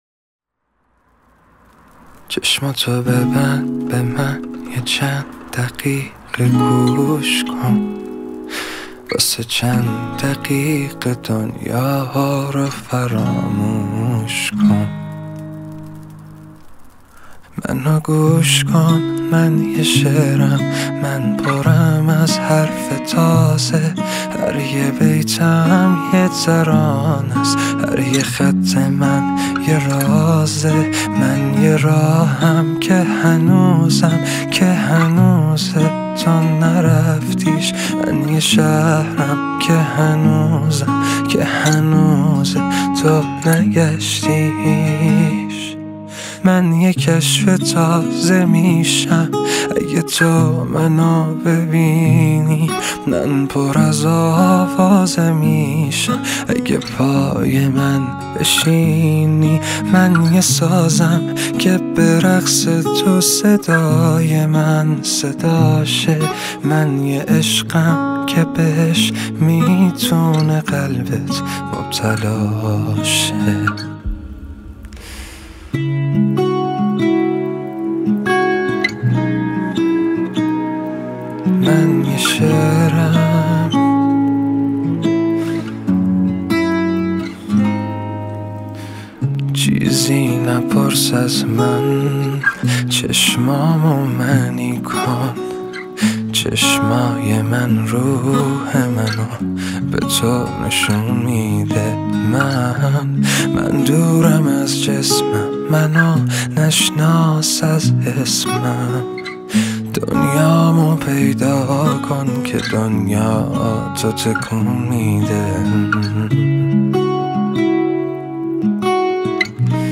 دانلود آهنگ سبک هیپ هاپ دانلود آهنگ غمگین